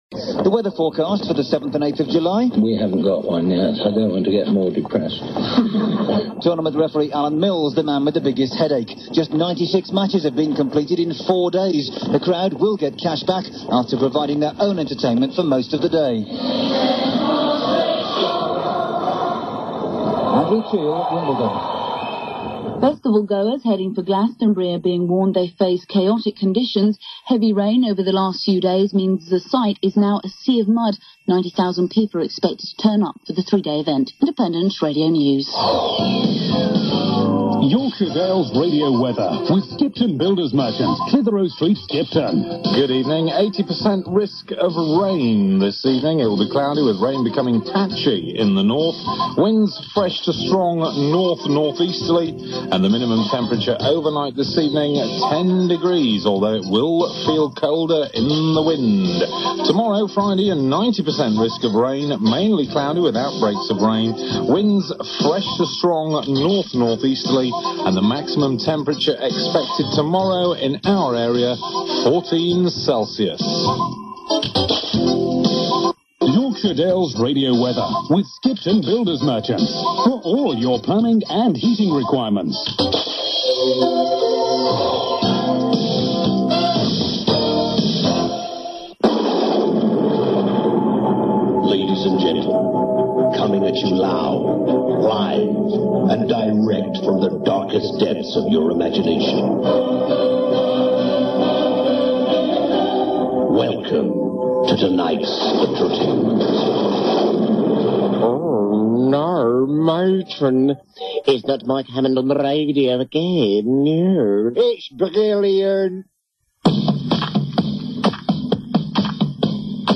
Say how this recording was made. Yorkshire Dales Radio was launched 4 May 1997 and re-launched in 1999 as Fresh AM, changed two years later to Fresh Radio (and changed in 2012 to Stray FM). Listen to a short fragment recorded in june 1997.